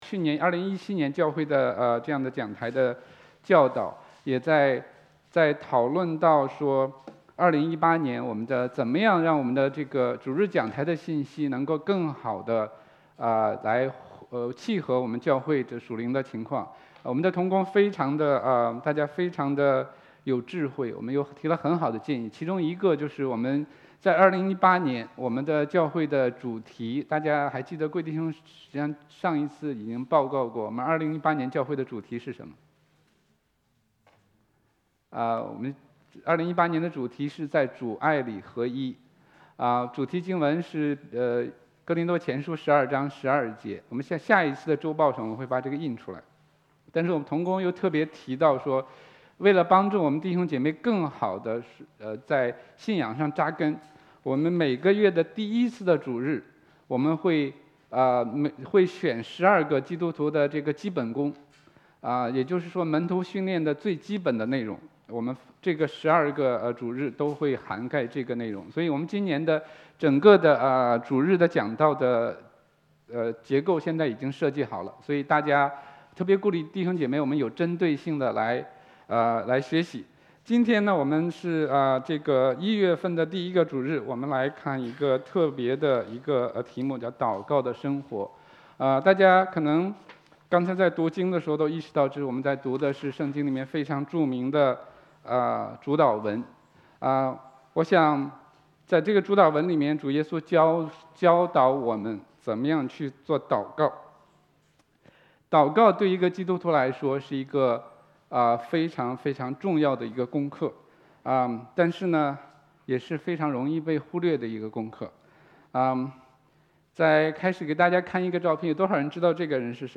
证道录音 | 拉法叶华人宣道会